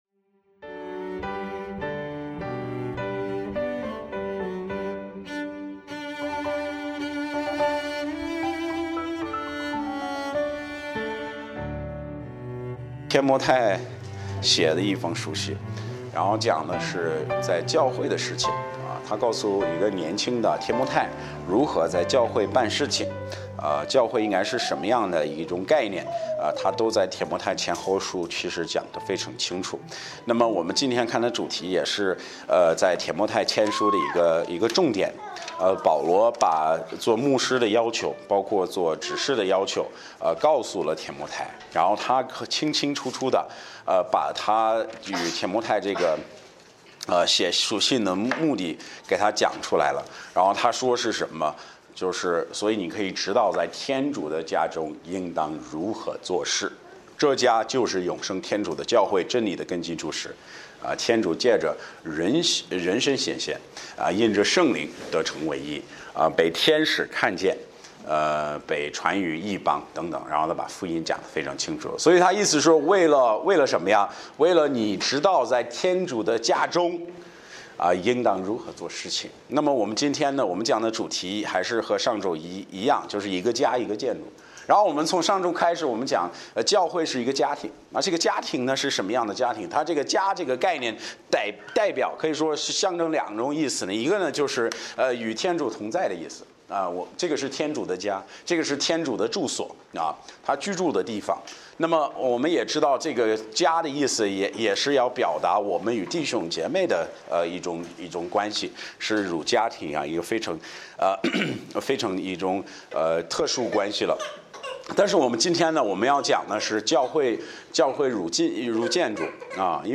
Bible Text: 提摩太前书3：14-16 | 讲道者